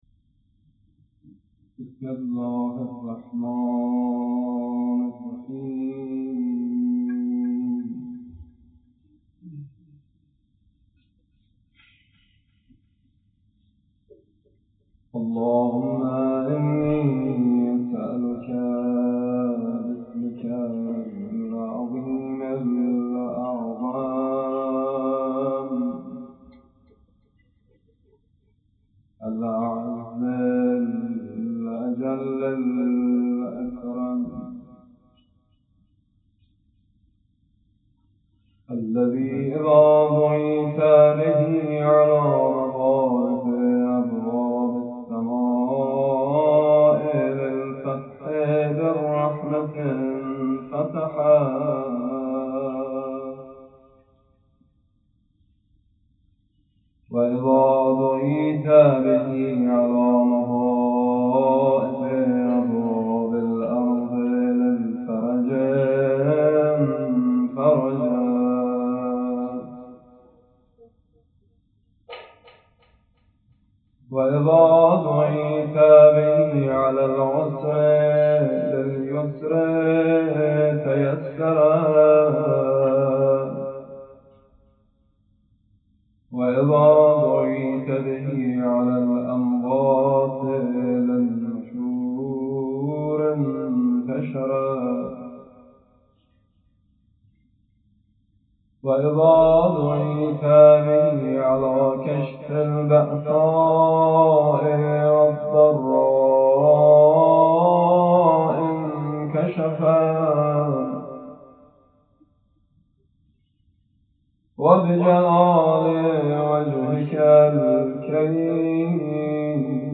قرائت دعای سمات 1 - ادعیه و زیارات - مداح شماره 1 | مکتب وحی
قرائت دعای سمات 1